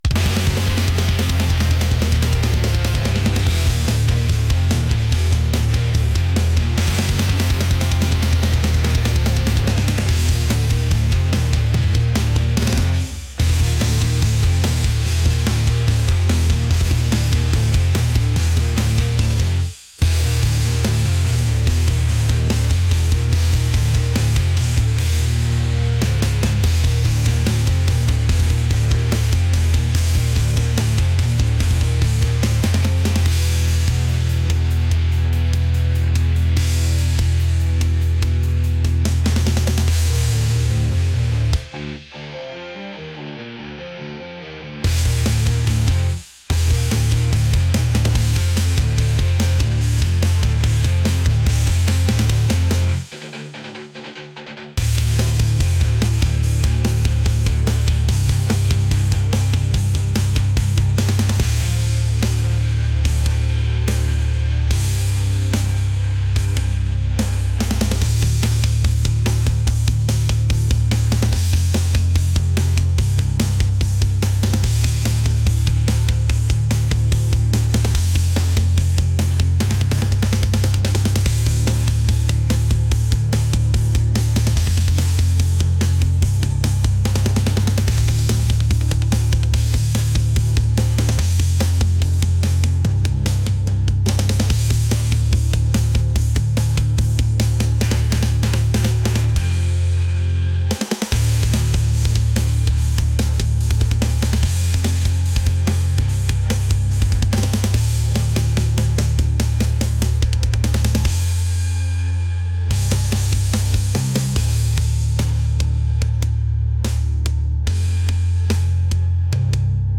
intense | rock